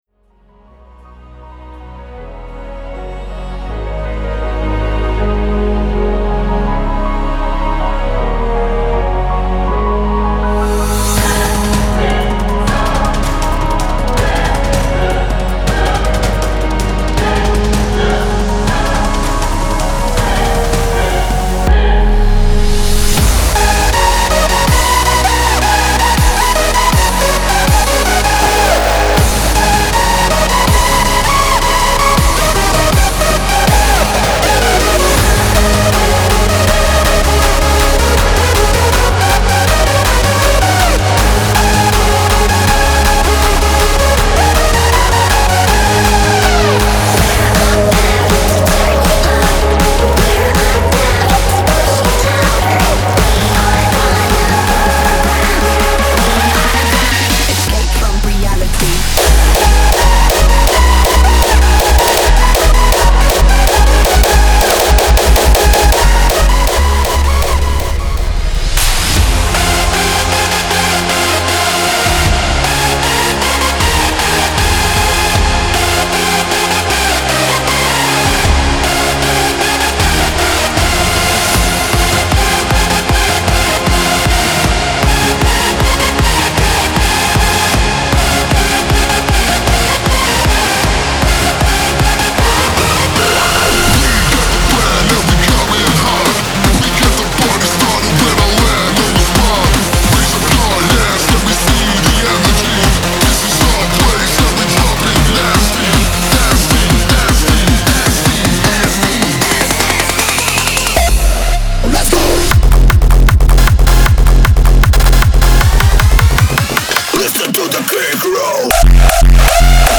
・Arrange